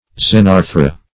Xenarthra \Xen*ar"thra\ prop. n.